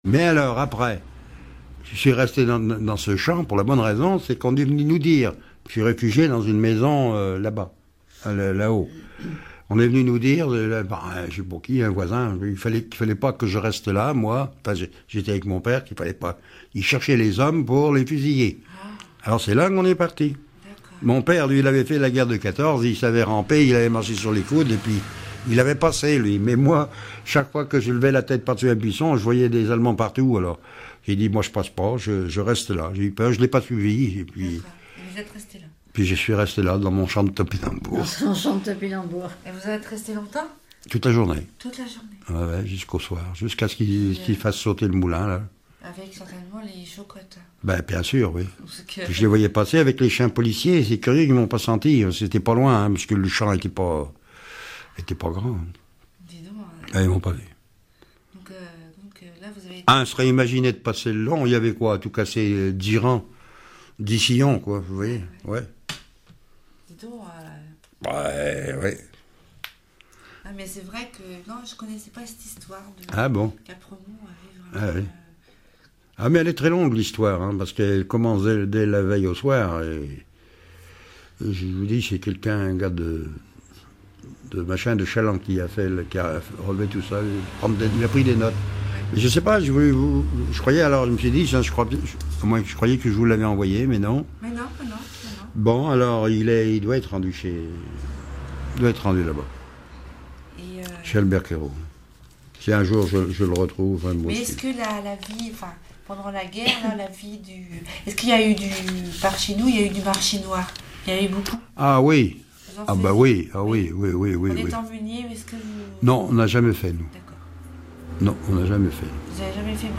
Témoignage sur l'occupation allemande et la meunerie
Catégorie Témoignage